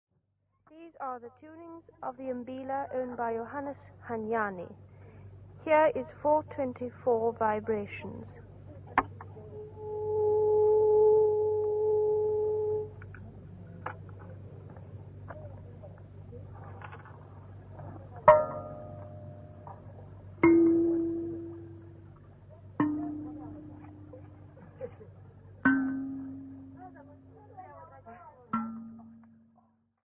Tuning of the mbila